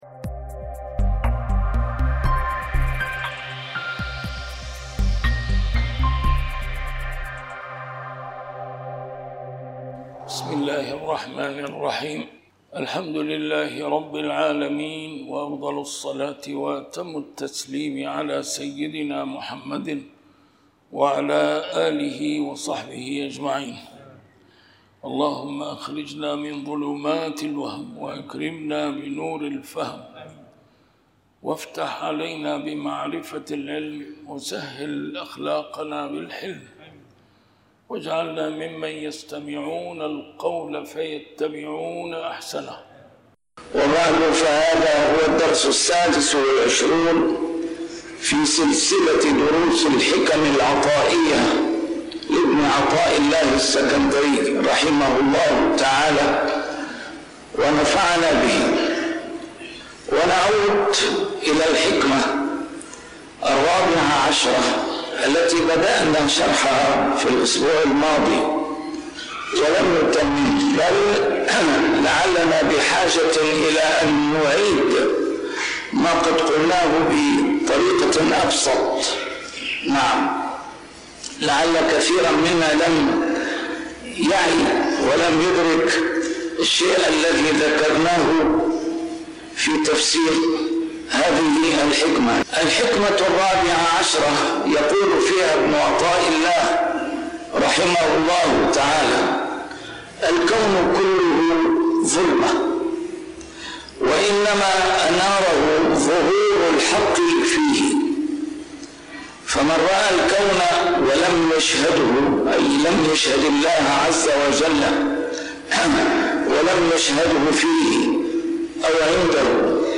A MARTYR SCHOLAR: IMAM MUHAMMAD SAEED RAMADAN AL-BOUTI - الدروس العلمية - شرح الحكم العطائية - الدرس رقم 26 شرح الحكمة 14